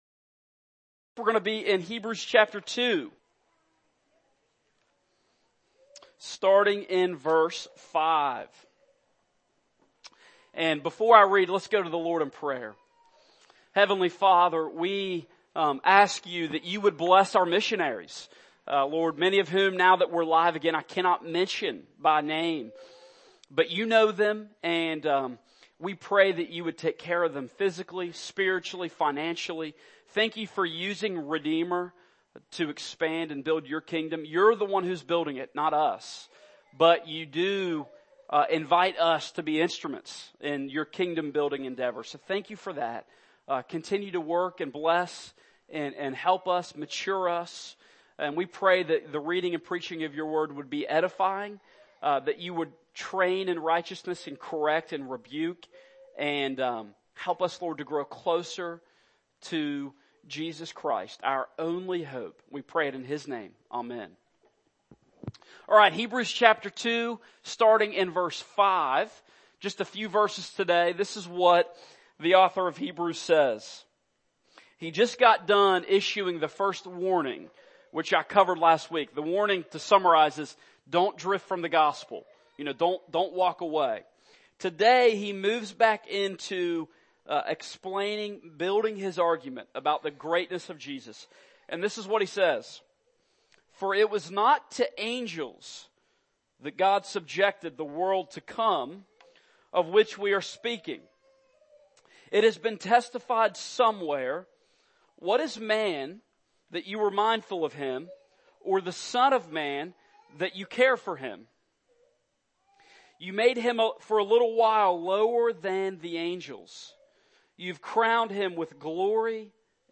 Passage: Hebrews 2:5-9 Service Type: Morning Service